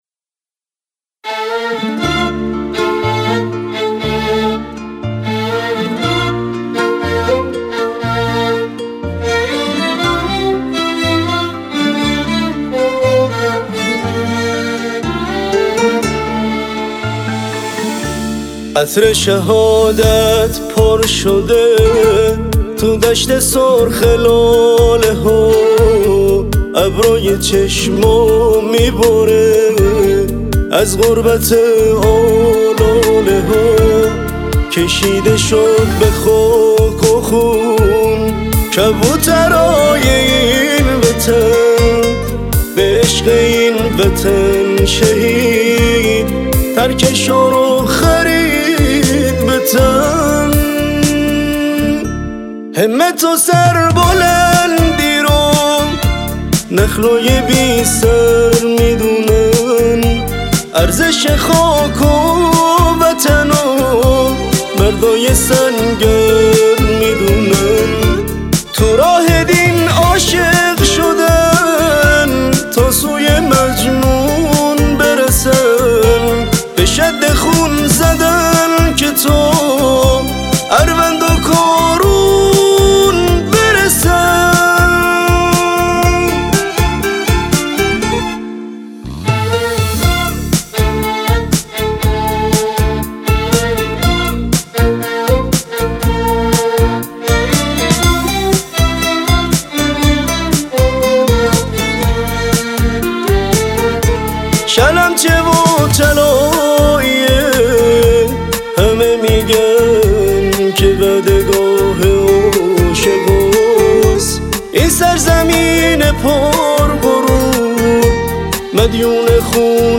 موسیقی با کلام